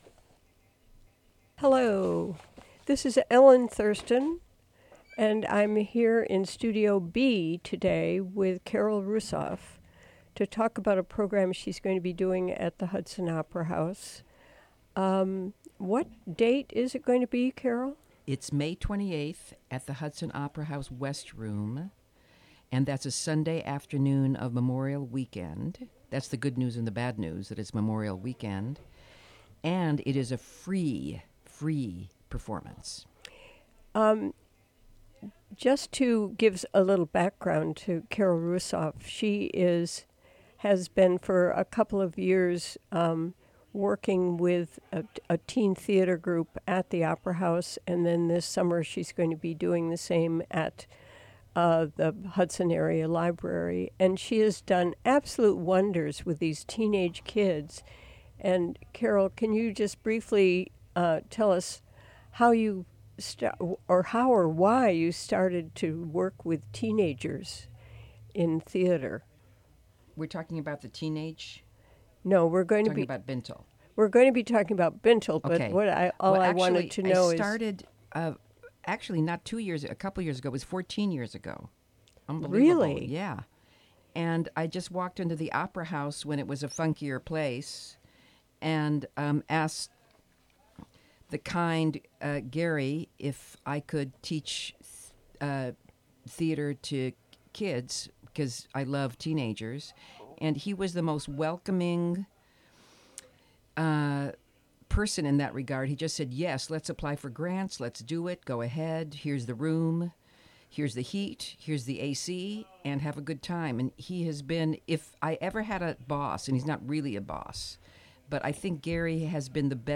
Prerecorded in the WGXC Hudson Studio on Thursday, May 11, 2017.